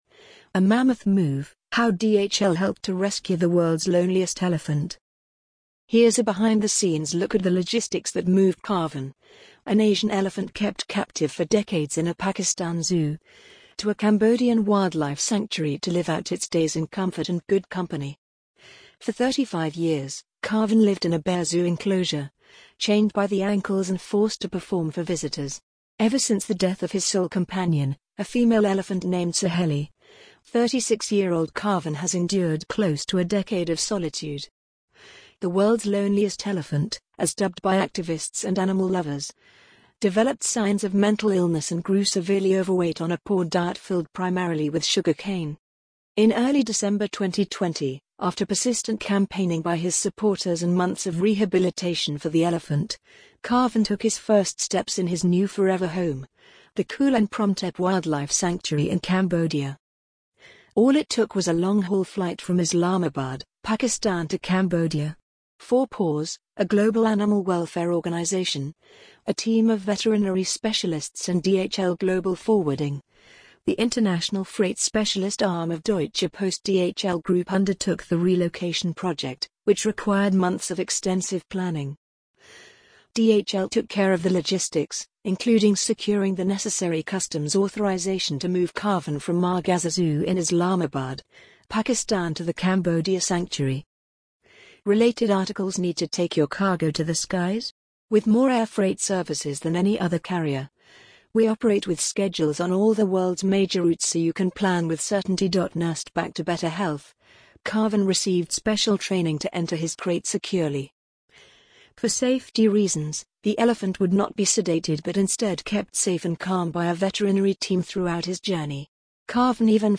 amazon_polly_9584.mp3